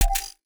UIClick_Next Button 04.wav